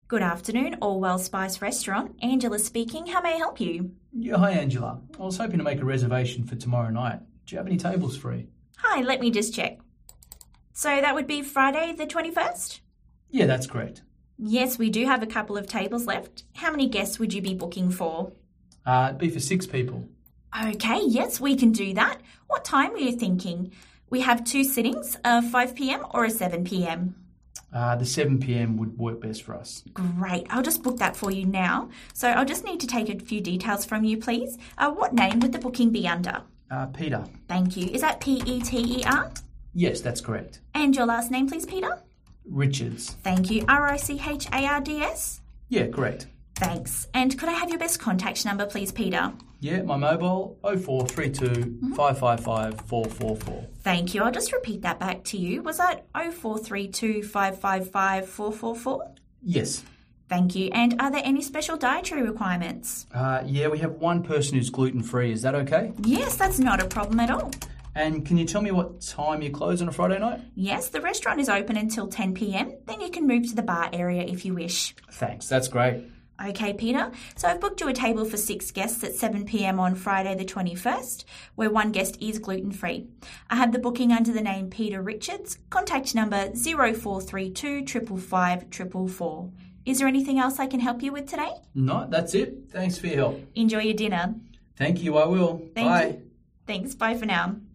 Australian receptionists
restaurant-reservations-remote-phone-answering-service-sample-call.mp3